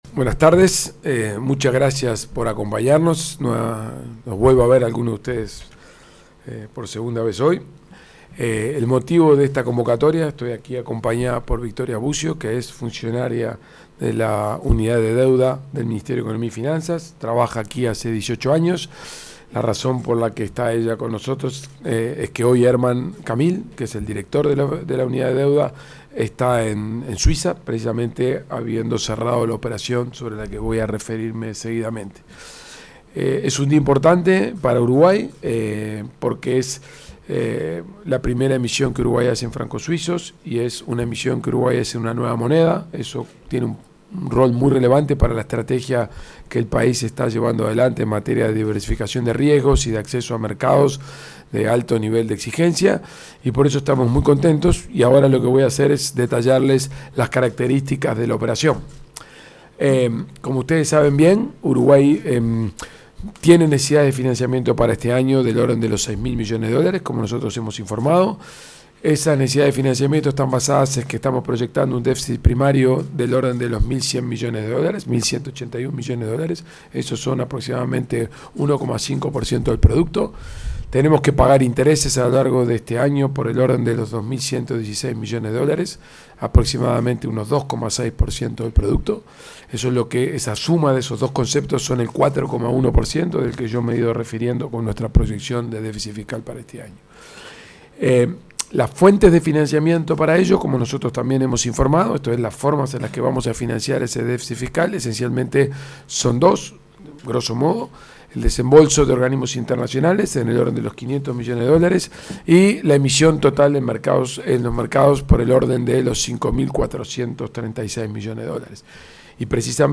Palabras del ministro de Economía y Finanzas, Gabriel Oddone